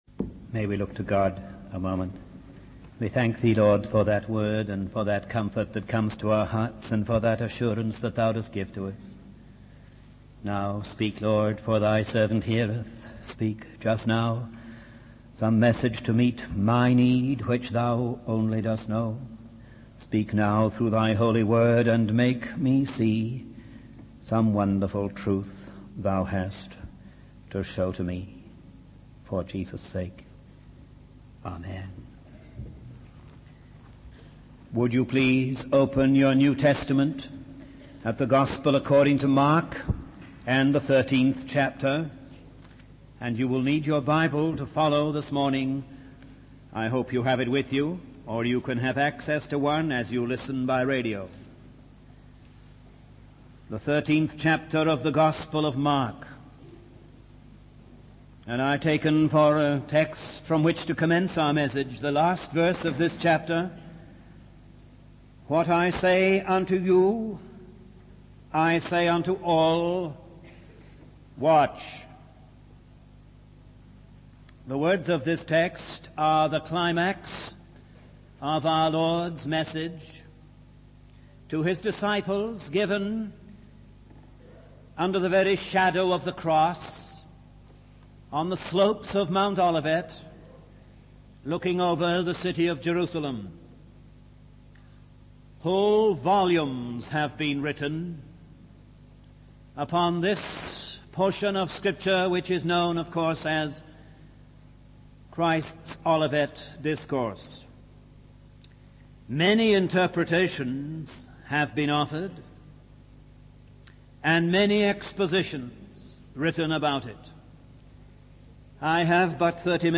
In this sermon, the preacher emphasizes the importance of watching and praying in relation to the work of God. He highlights that watching involves maintaining loyalty to Jesus Christ and having courage in the face of adversity.